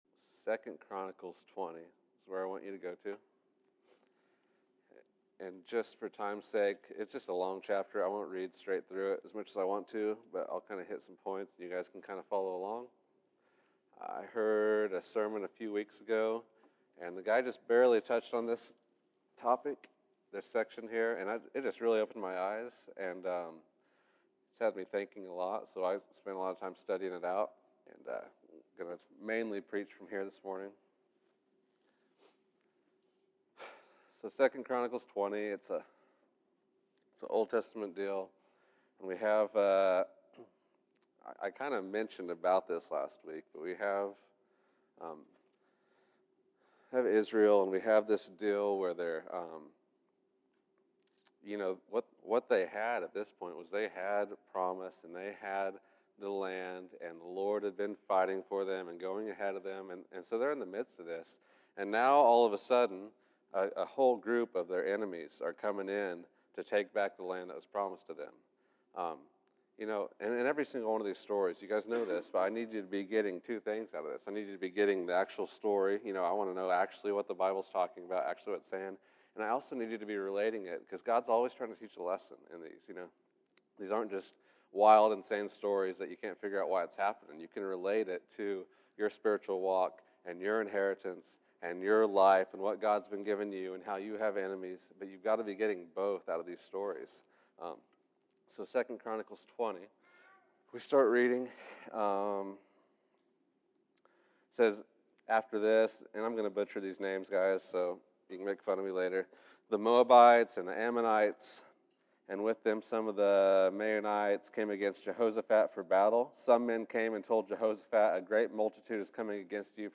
Worshipping in War June 07, 2015 Category: Sunday School